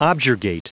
Prononciation du mot objurgate en anglais (fichier audio)
Prononciation du mot : objurgate